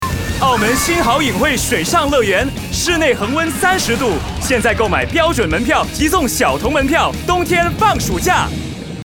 Trustworthy
Warm
Authoritative